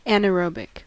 Ääntäminen
Vaihtoehtoiset kirjoitusmuodot anaërobic Ääntäminen US US : IPA : /ˌænəˈɹoʊbik/ Haettu sana löytyi näillä lähdekielillä: englanti Käännöksiä ei löytynyt valitulle kohdekielelle.